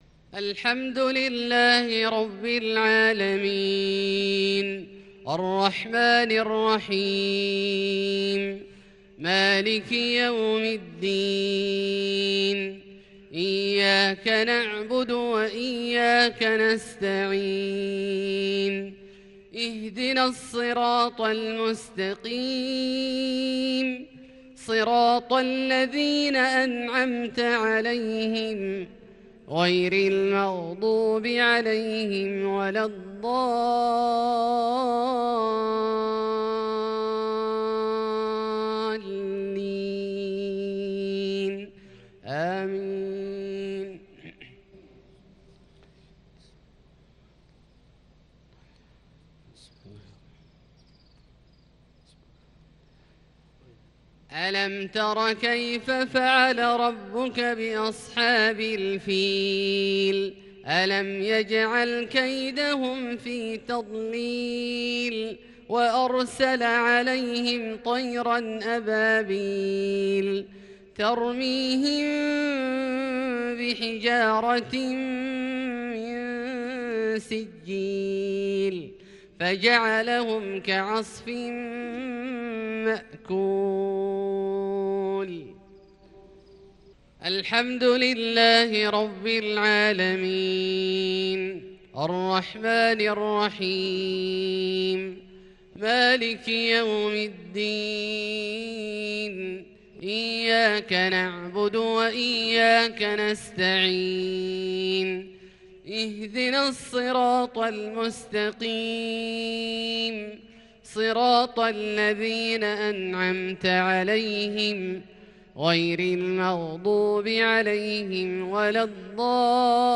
مغرب ٤-٣-١٤٤٢ سورتي الفيل والماعون > ١٤٤٢ هـ > الفروض - تلاوات عبدالله الجهني